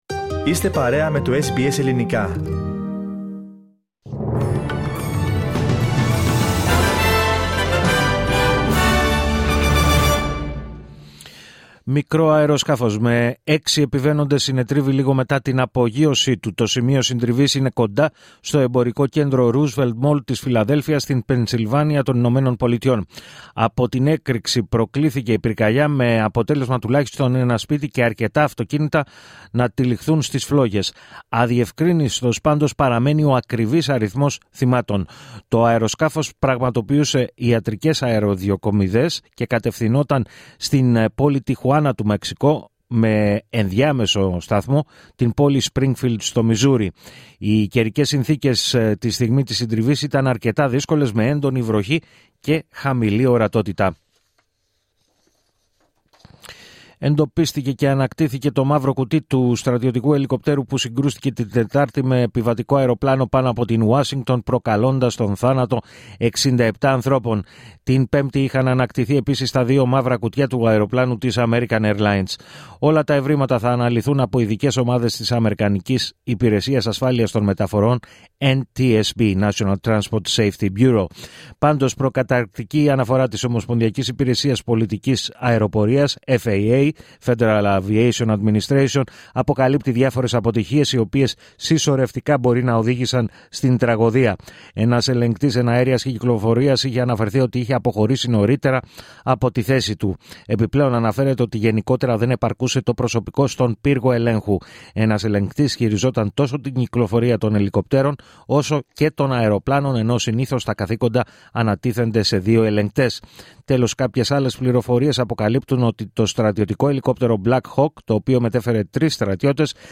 Δελτίο Ειδήσεων Σάββατο 1η Φεβρουαρίου 2025